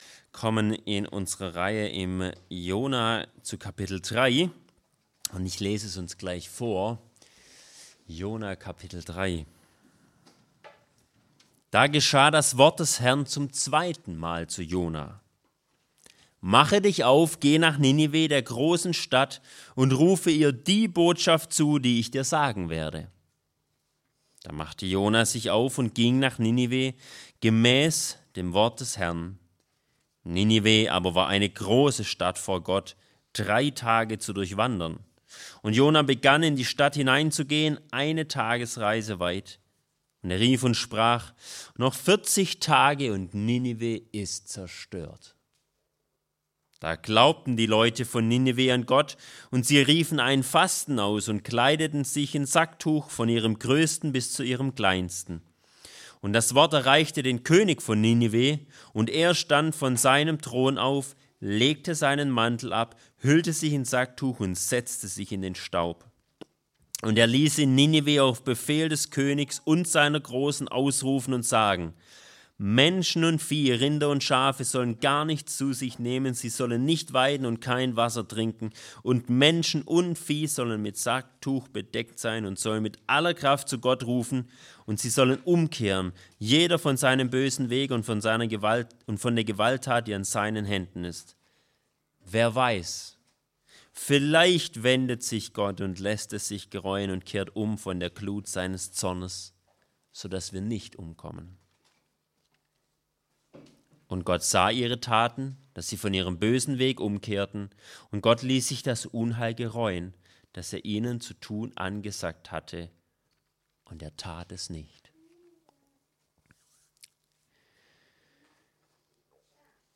Predigtreihe: Jona Auslegungsreihe